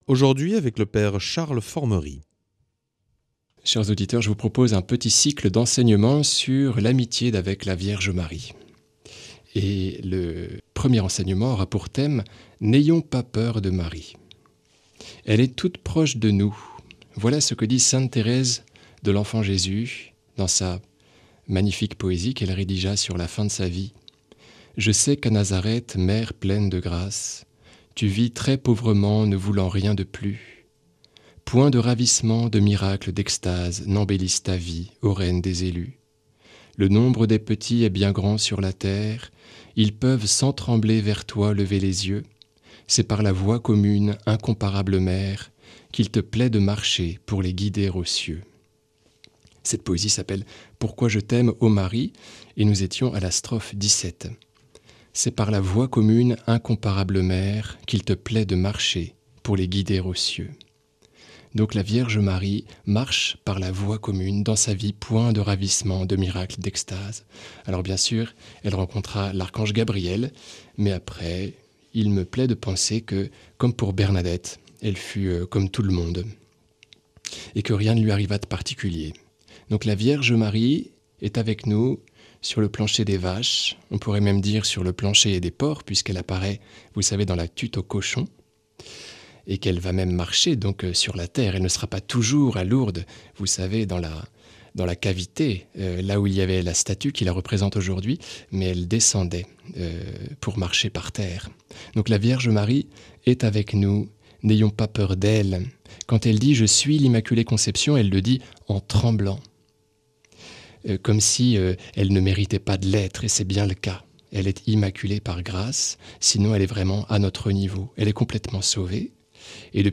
Enseignement Marial